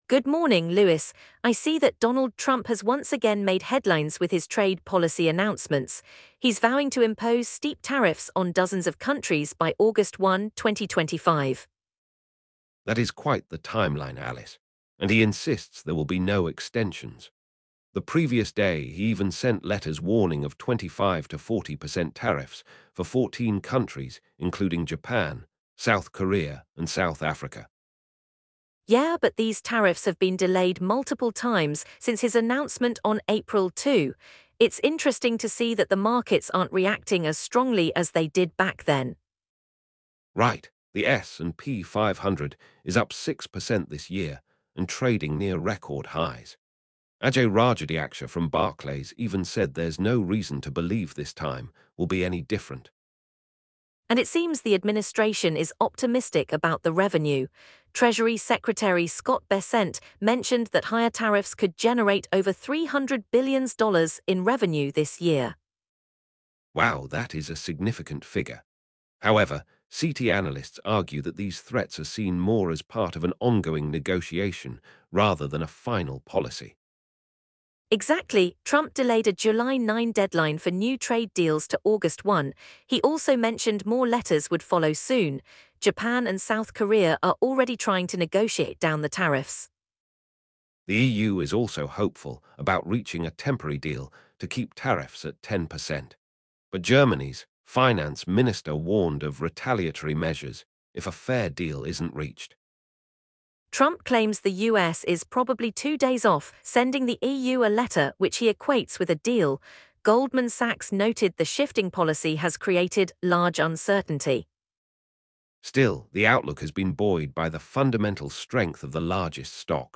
UK morning business news